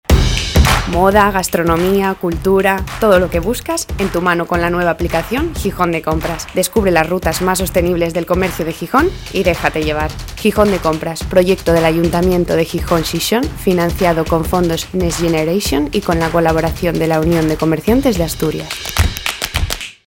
comercio_gijon_cuna.mp3